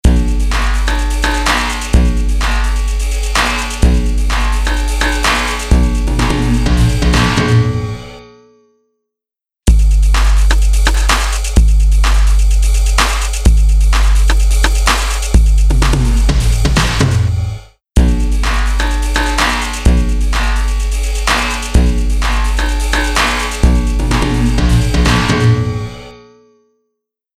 Tube Amp-style Spring Reverb
Spring | Electronic Drums | Preset: Resonation Nation
Spring-Eventide-Electronic-Drums-Resonation-Nation.mp3